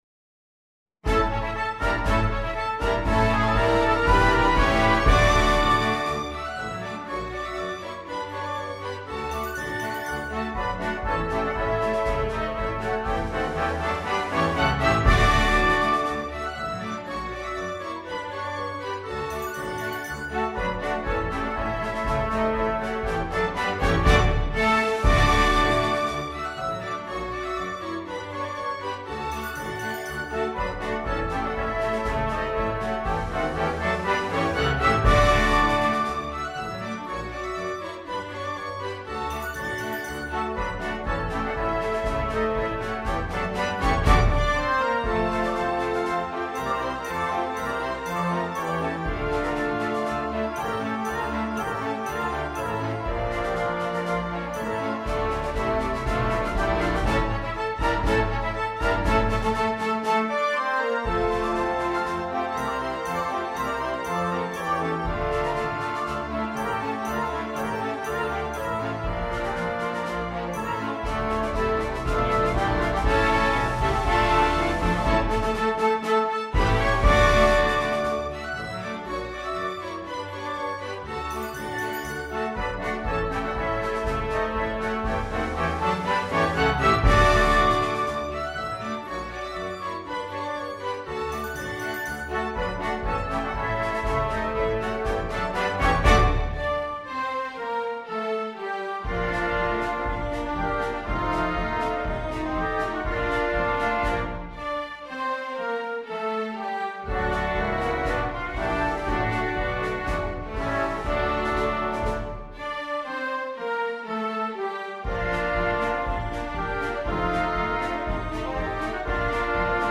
Title Cabaret Capers Subtitle Parisian March Dedication Composer Allen, Thomas S. Arranger Date 1913 Style March Instrumentation Salon Orchestra Score/Parts Download Audio File:Cabaret Capers.mp3 Notes